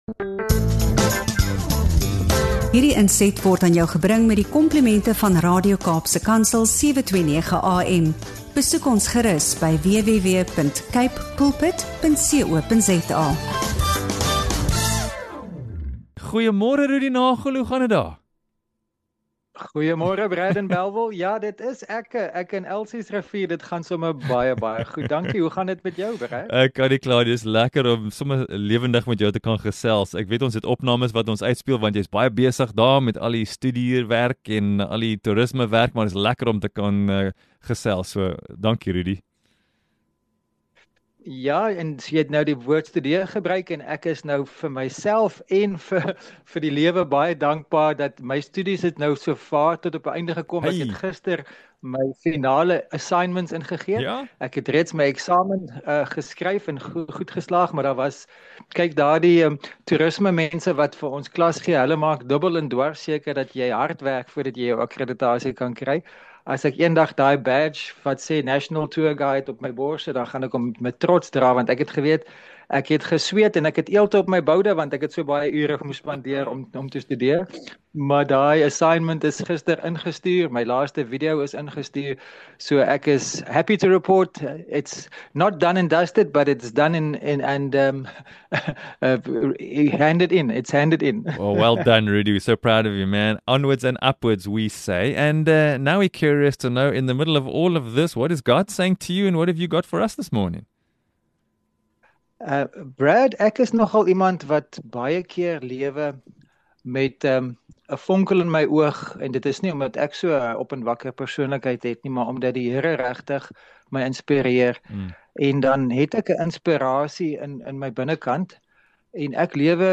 In ’n inspirerende gesprek op Radio Kaapse Kansel